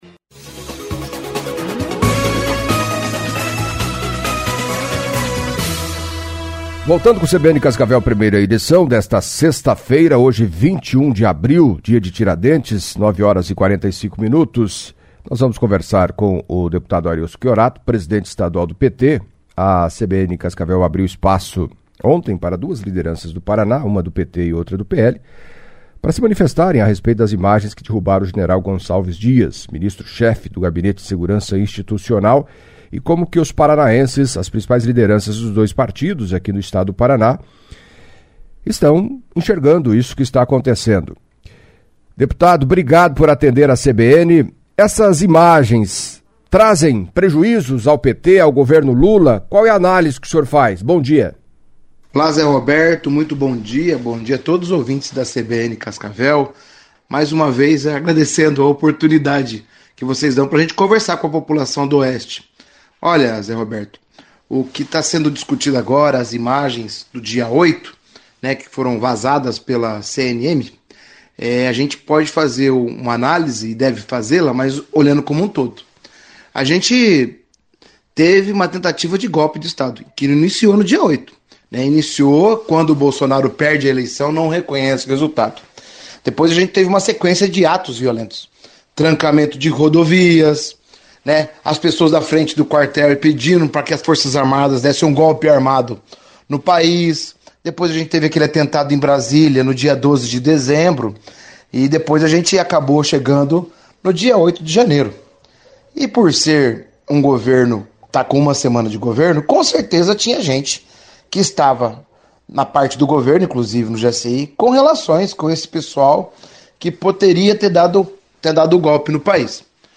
Nesta sexta-feira (21), o entrevistado foi o presidente estadual do PT, o deputado estadual Arilson Chiorato, acompanhe.